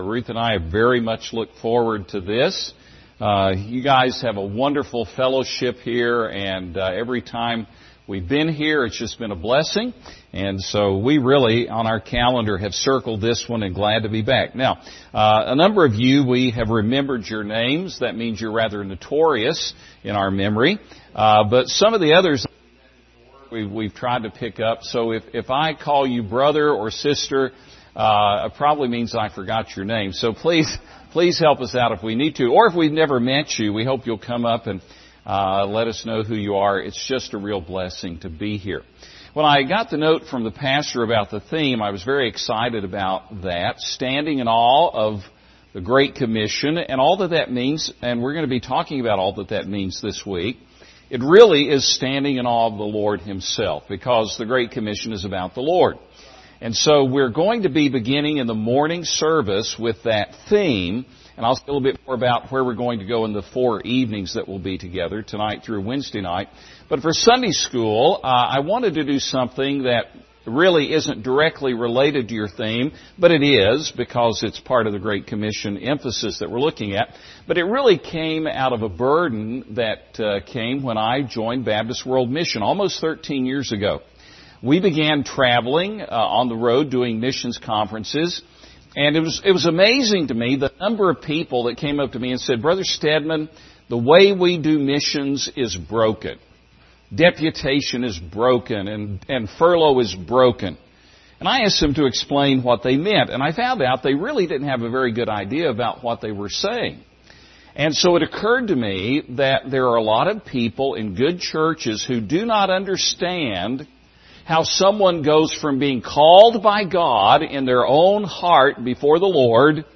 Series: 2021 Missions Conference